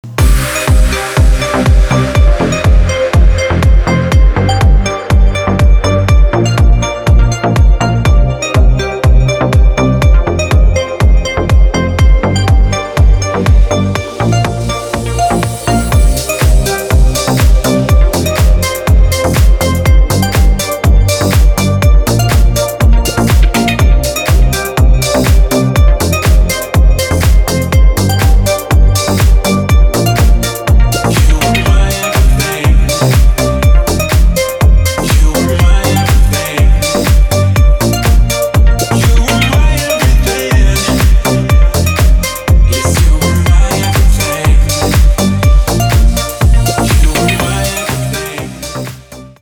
• Качество: 320, Stereo
deep house